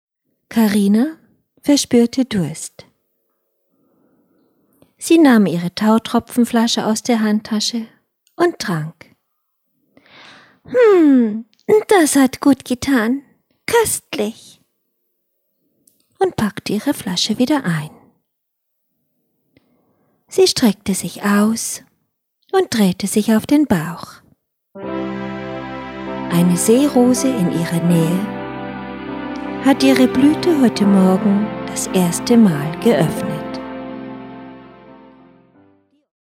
Hörgeschichte mit GEMA-freier Musik auf MP3-CD
Die einzelnen Episoden werden mit einer wundervollen Musik übergeleitet und machen das Ganze zu einem einmaligen Hörerlebnis.